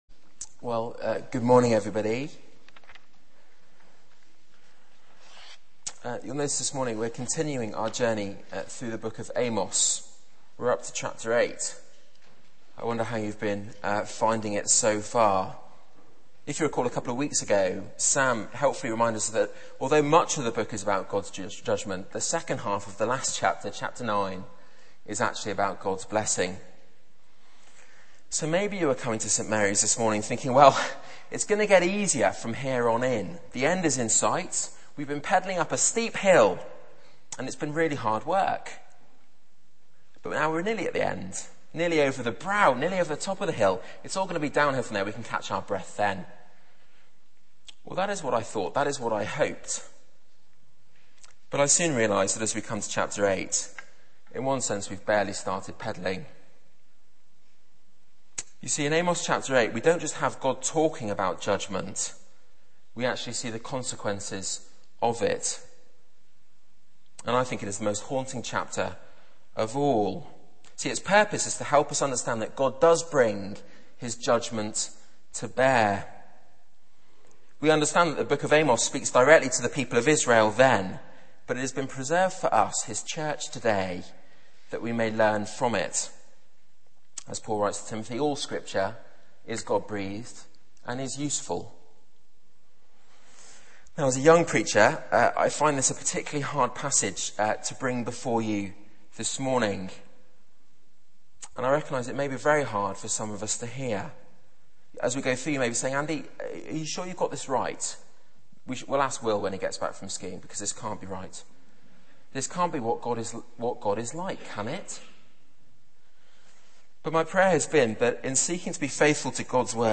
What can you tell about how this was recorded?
Media for 9:15am Service on Sun 14th Feb 2010 09:15 Speaker: Passage: Amos 8 Series: Amos Theme: The Autumn of Opportunity Sermon Search the media library There are recordings here going back several years.